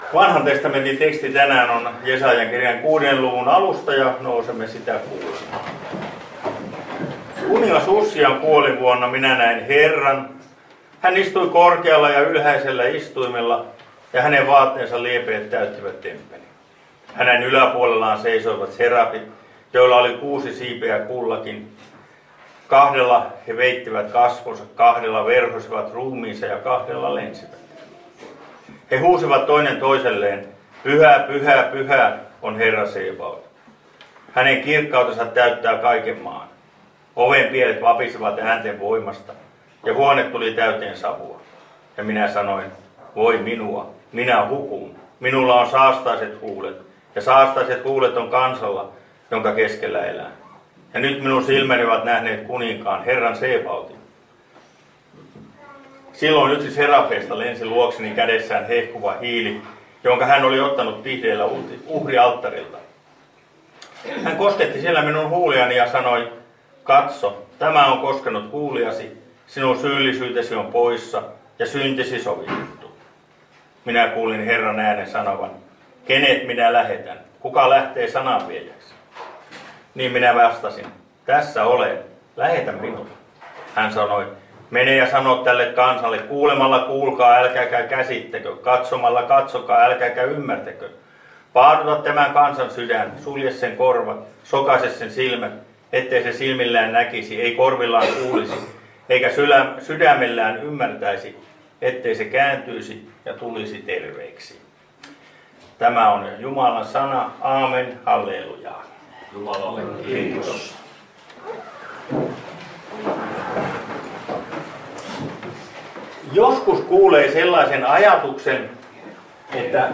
Lappeenranta